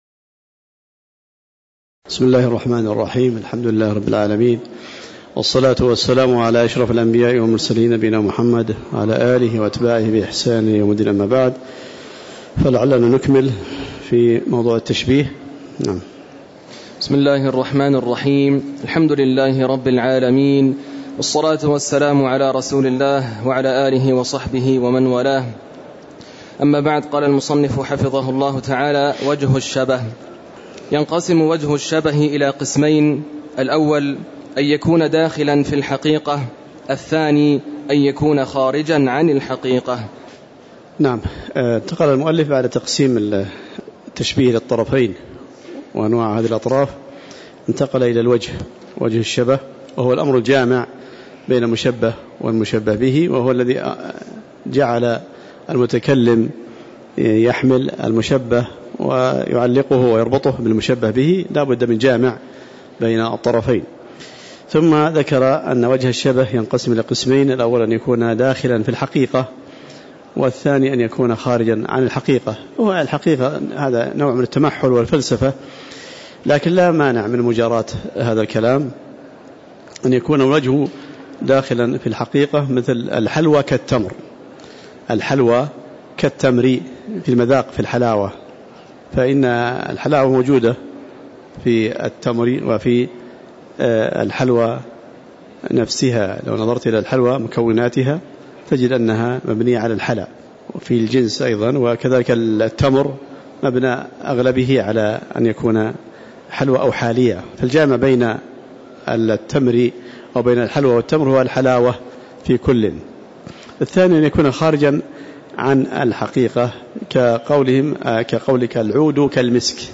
تاريخ النشر ١١ صفر ١٤٤٠ هـ المكان: المسجد النبوي الشيخ